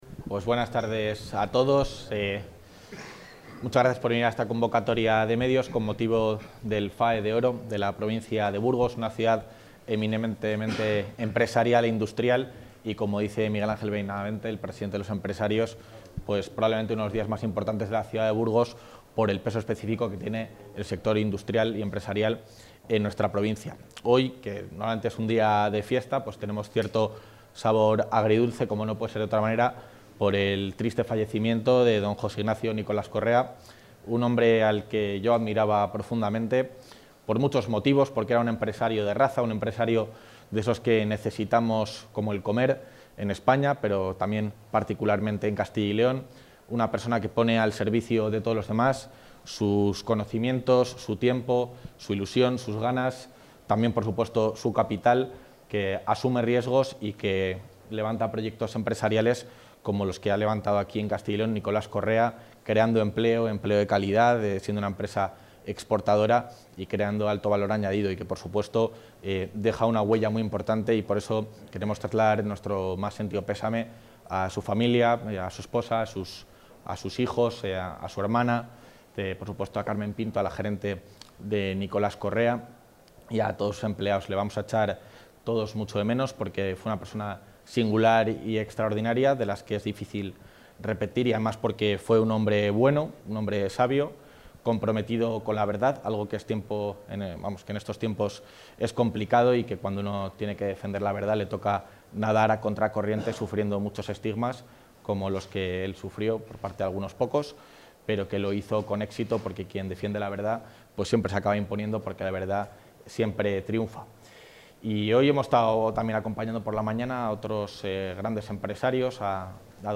Intervención del vicepresidente de la Junta.
Intervención del vicepresidente de la Junta Gala de entrega de los premios FAE de Oro Gala de entrega de los premios FAE de Oro Gala de entrega de los premios FAE de Oro Gala de entrega de los premios FAE de Oro